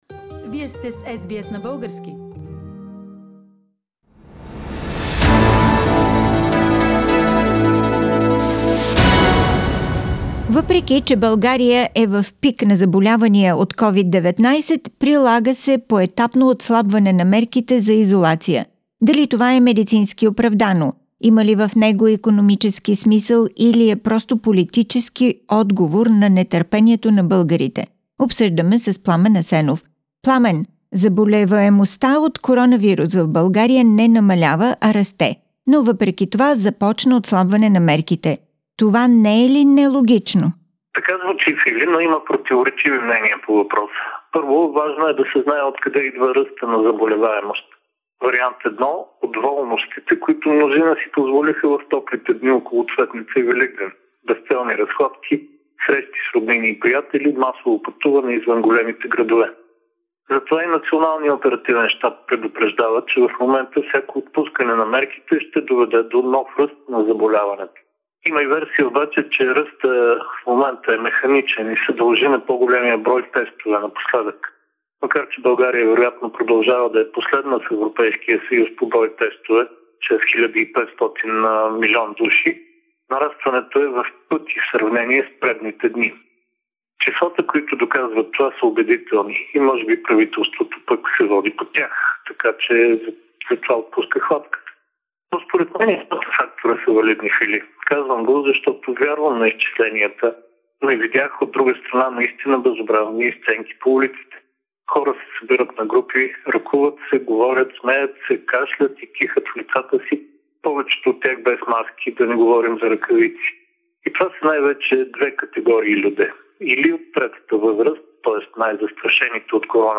Political analysis